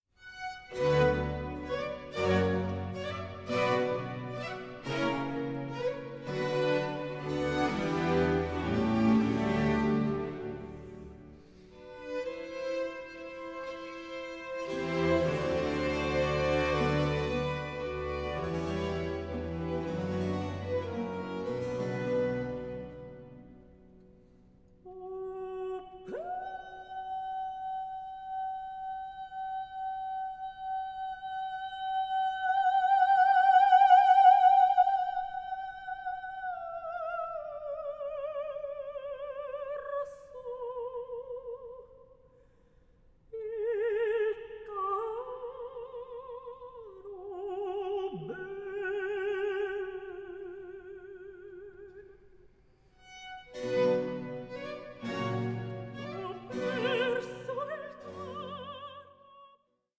period-instrument group